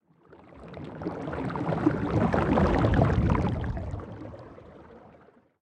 Sfx_creature_glowwhale_swim_slow_02.ogg